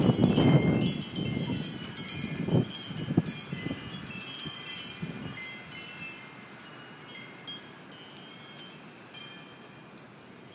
and as i walked home last night, the clock read 1:23. the rain had stopped but the wind was still moving strong across the sky. the sound of the fir tree echoed down the hollow street. the world was wet and crisp. gloomy but alive. the watercolour reflection of the lights, saying: stop. and: go. smudges of colour on the sombre street. and my heart felt heavy. but i had that image of the tree. deeply rooted. arms outstretched. swaying now. bending in the wind. the storm rustling and ravaging. but the silhouette stood strong against this dark night. the natural tragedy of it all. the cycle of it all.
and as i kept walking, there were wind chimes. so many of them. making music of the ferocity. a gentle sound that stood in contrast to the intensity of this eve.
chimes.m4a